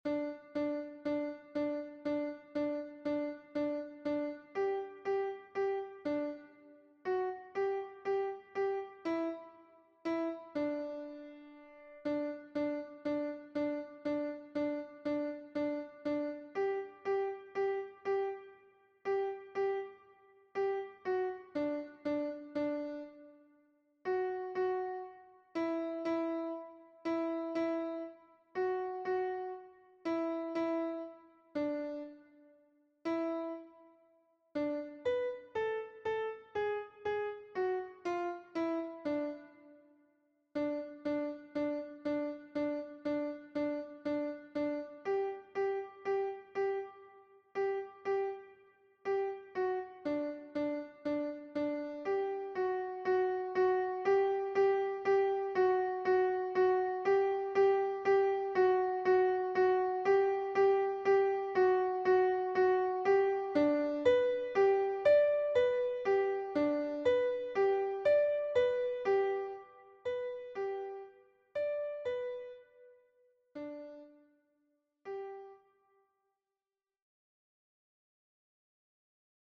MP3 version piano
Alto 2